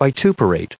1841_vituperate.ogg